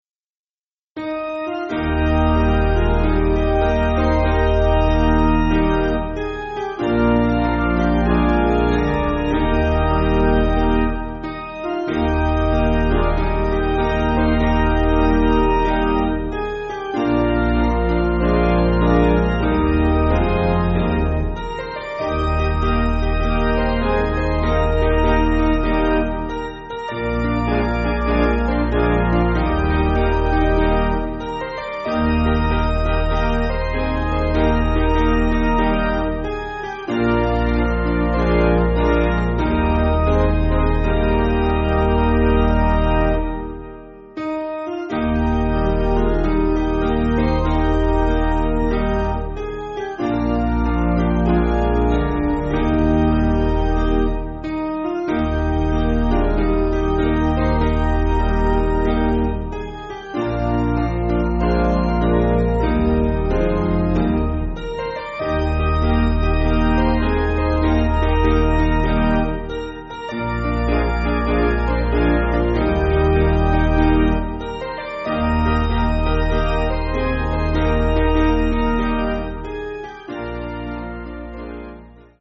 Basic Piano & Organ
(CM)   4/Eb